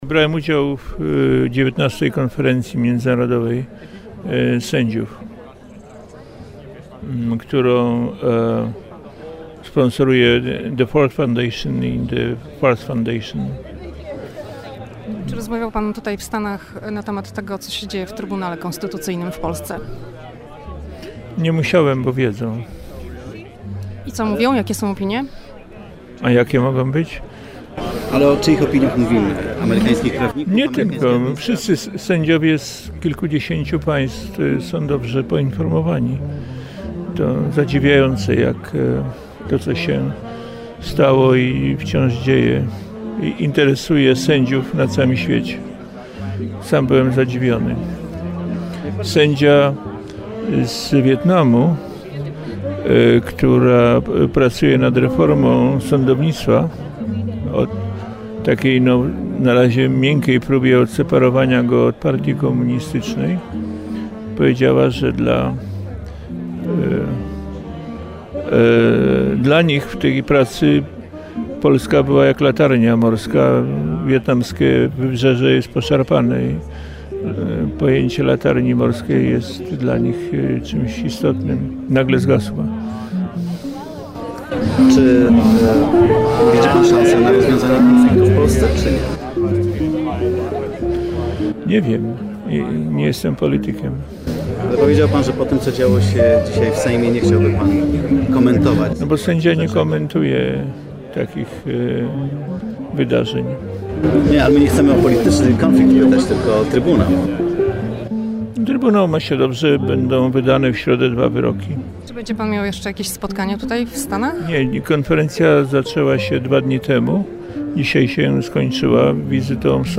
Profesor Rzepliński, w rozmowie z dziennikarzami powiedział, że sytuacja polskiego Trybunału Konstytucyjnego znana jest sędziom z innych krajów świata, jednak nie była ona tematem dyskusji podczas konferencji w Waszyngtonie, dlatego też nie zagłębiano się w rozmowę o sprawach polskich.